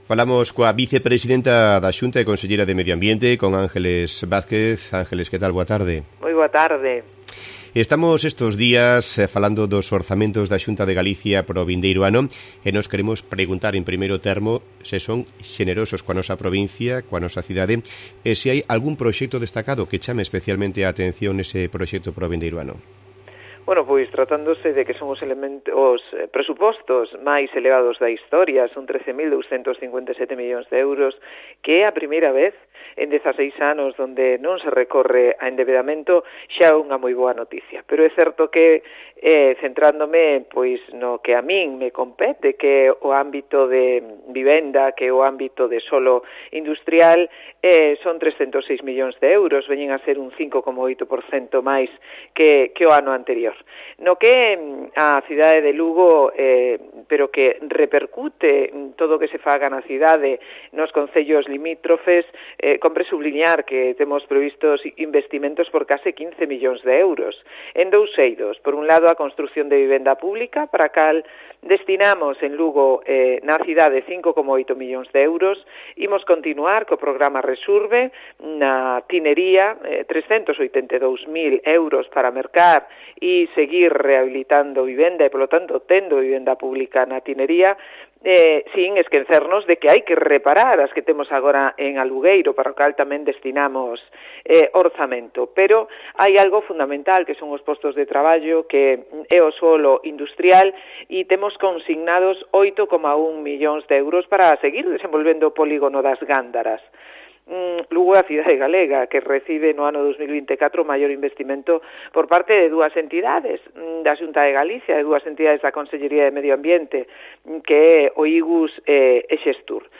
La vicepresidenta de la Xunta habló en la Cope sobre el PXOM pendiente y las inversiones en Lugo